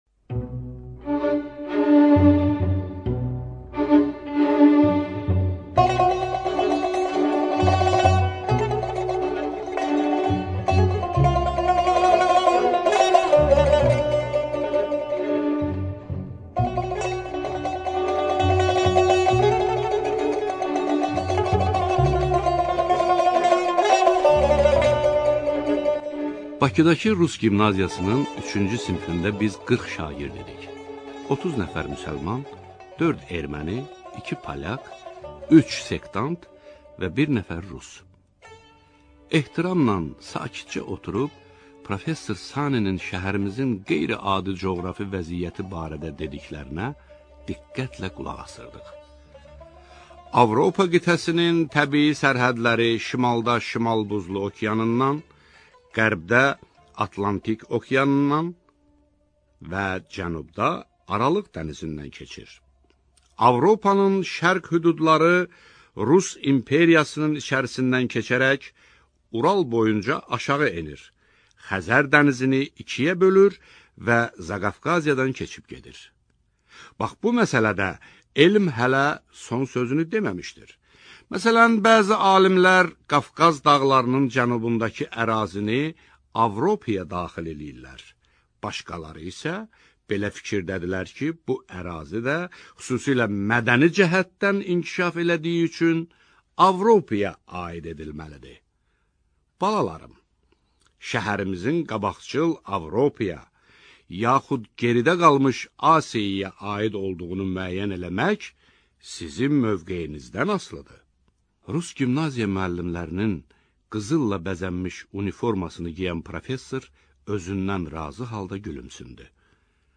Студия звукозаписиСтудия Азербайджанского общества слепых